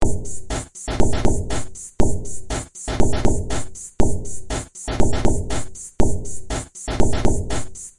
描述：这一组的第四组，有两个主要的节奏打击乐轨道的建立。
标签： 140 bpm Weird Loops Drum Loops 561.31 KB wav Key : Unknown
声道立体声